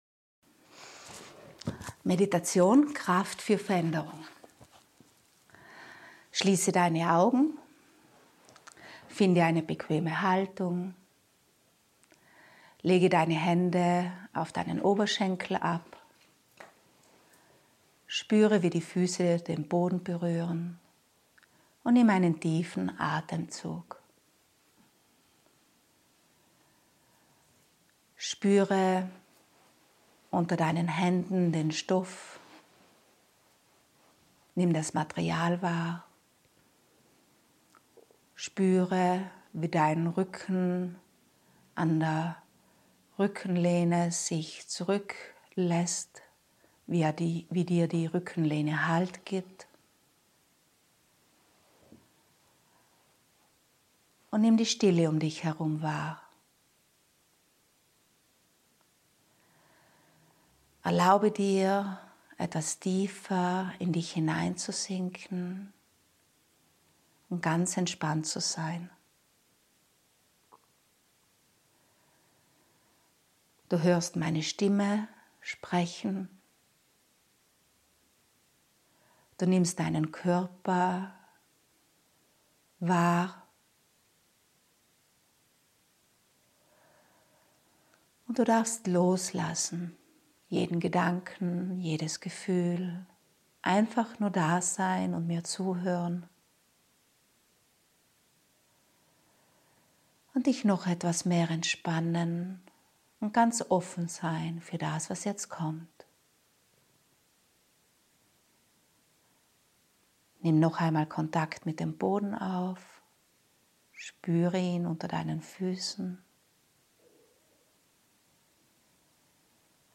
Diese Meditation schenkt dir Kraft und Vertrauen für anstehende Veränderungen. Die Meditation habe ich in einem meiner Seminare frei gesprochen und aufgenommen.